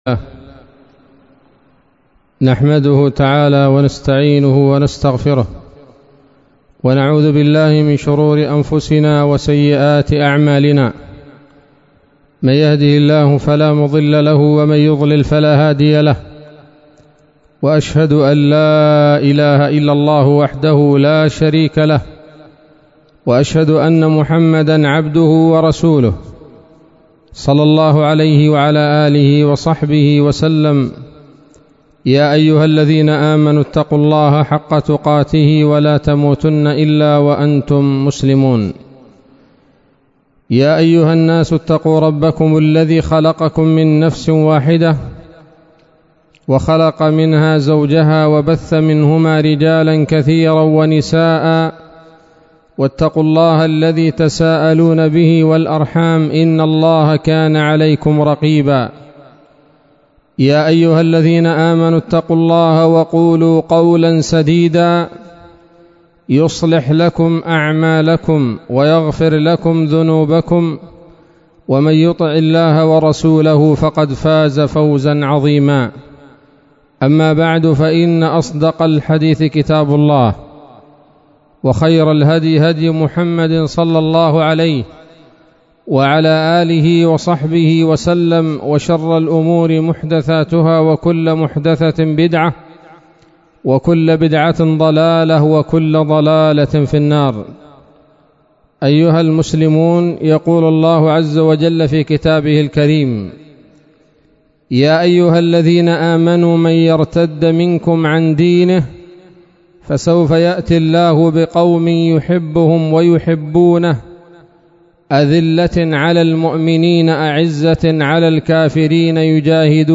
خطبة جمعة بعنوان: (( يمانيون من صحب الرسول ﷺ : زيد بن حارثة وعمار بن ياسر )) 02 ربيع الأول 1443 هـ